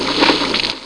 shlurp.mp3